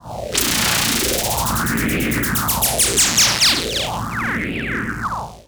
Roland E Noises
Roland E Noise 16.wav